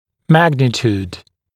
[‘mægnɪt(j)uːd][‘мэгнит(й)у:д ]величина